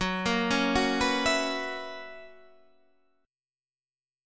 F#11 Chord
Listen to F#11 strummed